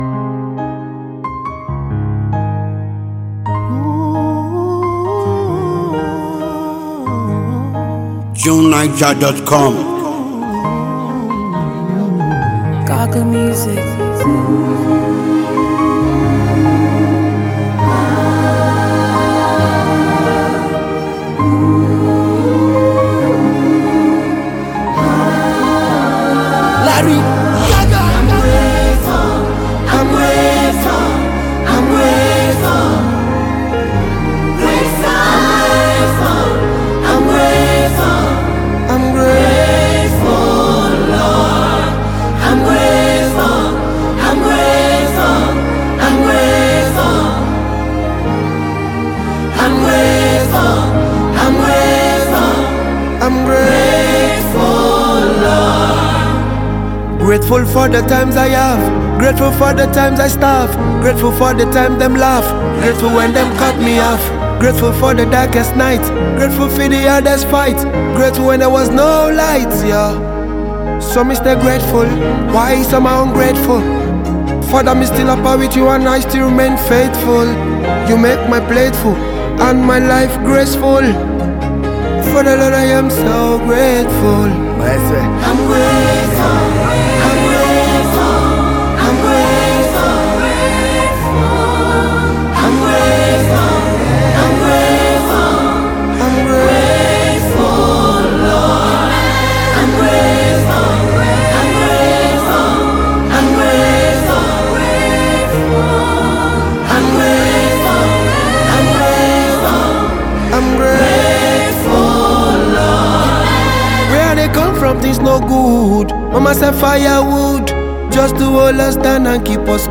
throbbing